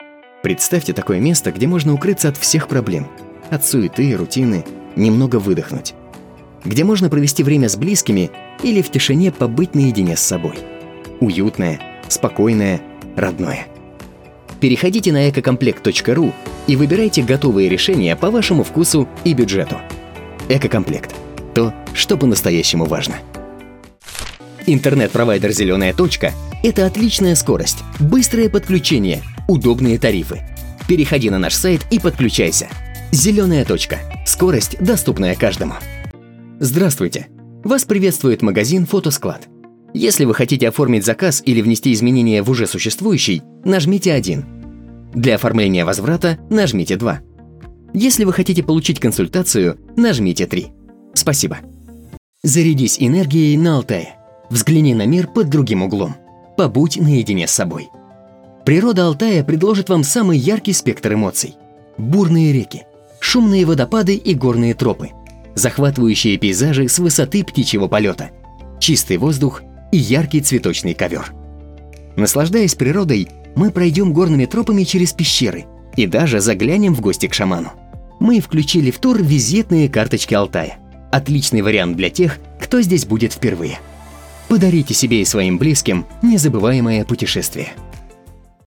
Диктор, актёр озвучивания, у микрофона более пяти лет.
Молодой, энергичный, если нужно романтичный голос.
Тракт: Микрофон SE Electronics X1 S, звуковая карта Arturia MiniFuse 2, акустически оборудованное помещение (домашняя студия).